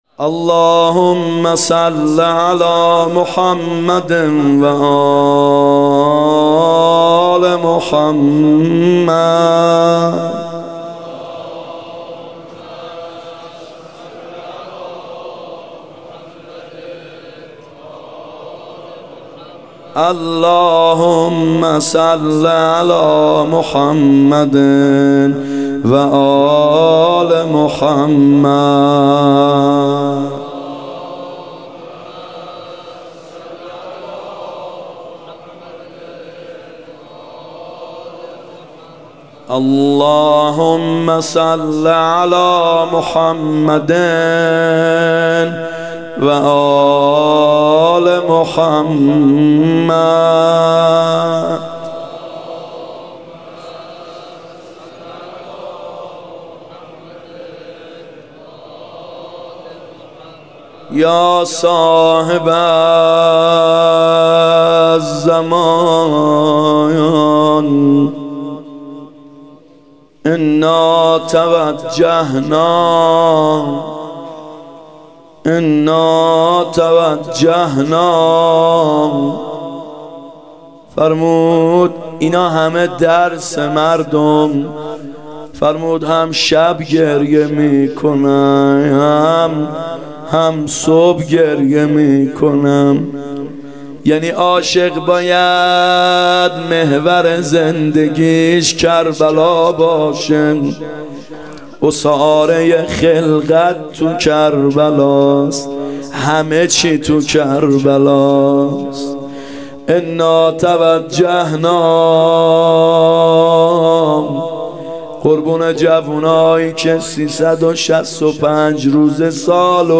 محرم 94 شب چهارم روضه
محرم 94(هیات یا مهدی عج)